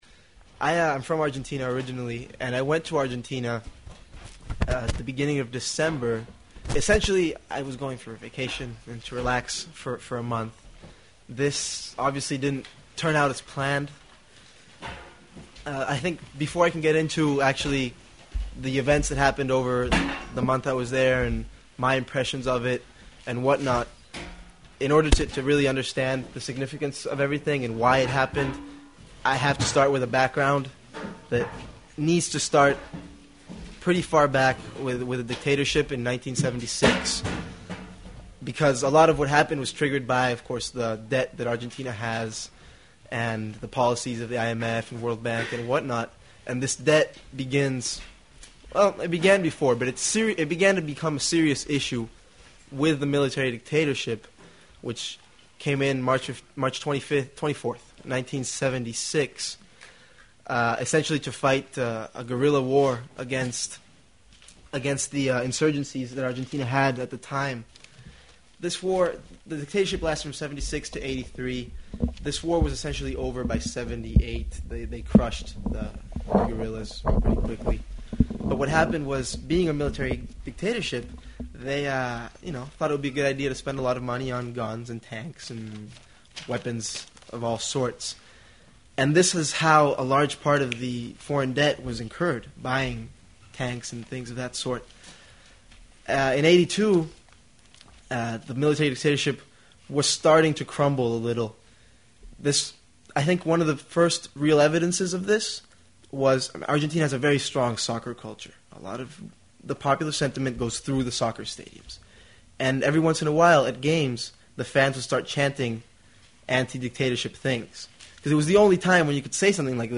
In our second hour, an eyewitness to the Argentine December uprising.